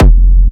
• Industrial Techno Kick oneshot SC - F.wav
Nicely shaped, crispy and reverbed industrial techno kick, used for hard techno, peak time techno and other hard related genres.
Industrial_Techno_Kick_oneshot_SC_-_F__KkV.wav